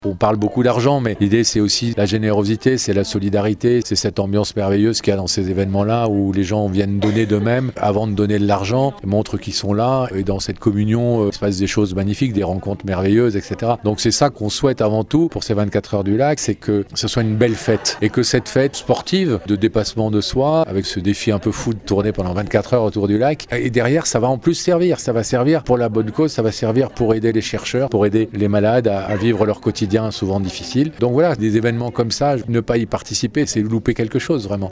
Le parrain de cet évenement cette année n’est autre que l’animateur et producteur de télévision Pascal Bataille, devenu ambassadeur de l’association Arsla.